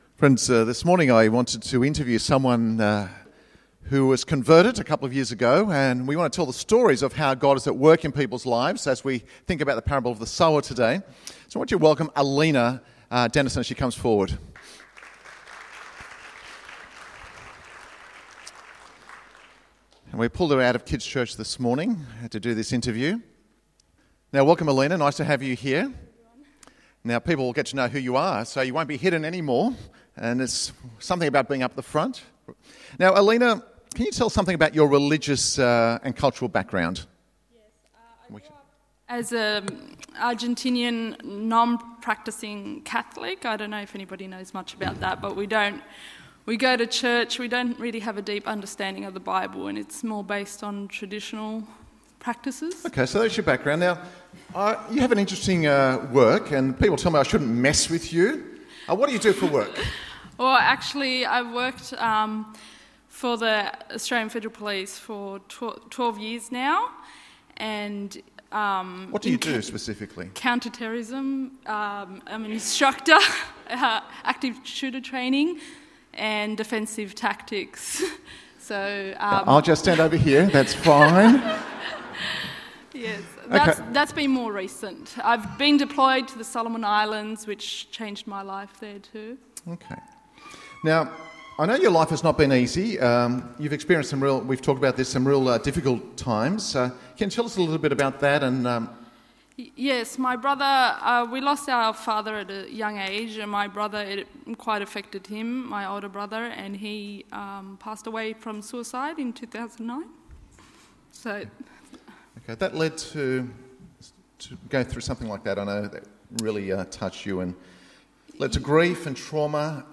continuing in our 'Living for King and Kingdom' series. Audio for Download: Mandarin service Mark Living for King and Kingdom